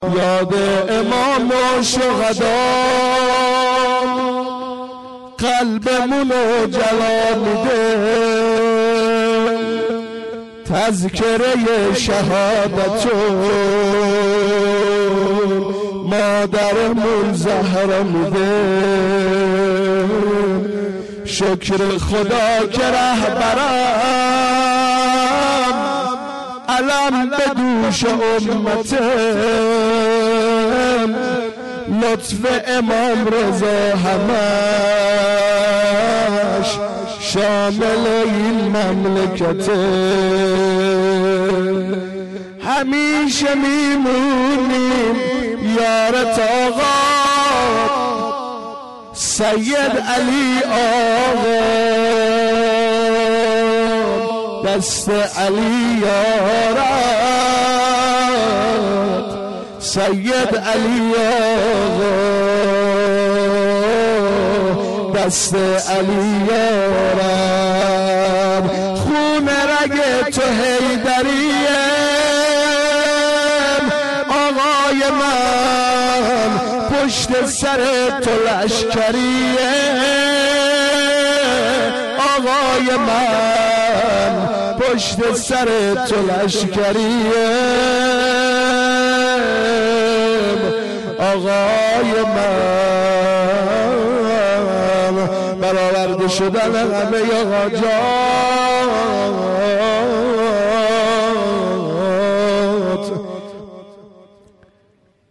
بخش دوم سینه زنی ظهر روز شهادت امام صادق (علیه السلام) 1436